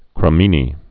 (krə-mēnē)